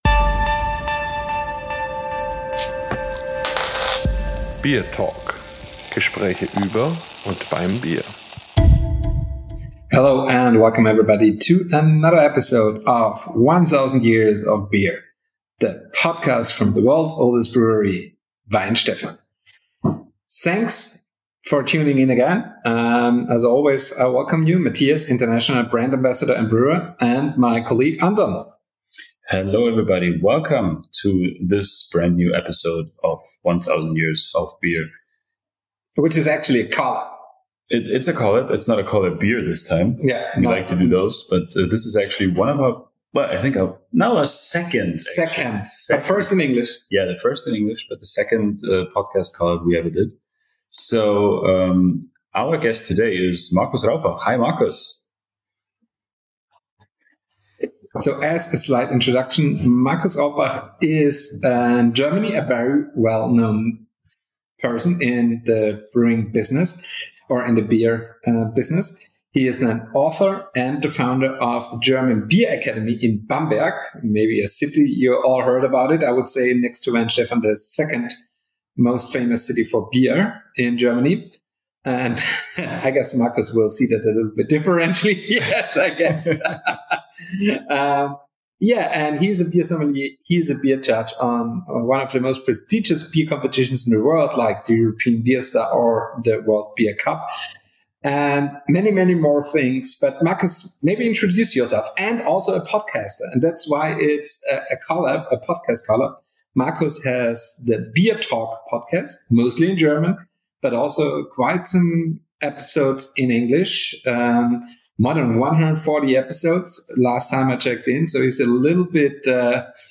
This time, the conversation dives deep into the world of beer judging, exploring what it takes to evaluate beers with a critical eye, as well as the intricacies of competitions and tasting techniques. In addition, the episode covers a broad range of other beer-related topics, from the brewing traditions of the world’s oldest brewery to modern trends and scientific insights into brewing processes. Expect a blend of expert knowledge, entertaining stories, and personal insights.